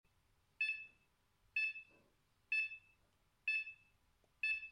• Der Vario-Ton variiert in Frequenz und Tonhöhe je nach vertikaler Geschwindigkeit
Schwache Thermik (-0.2 bis +0.8 m/s)